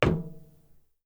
Added head bob & footstep SFX
metal2.wav